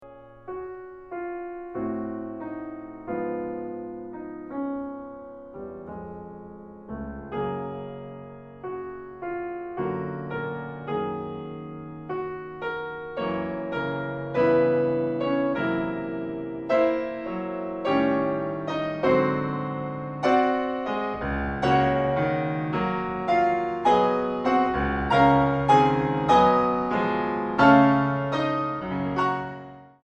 Piano Bösendorfer 290 Imperial.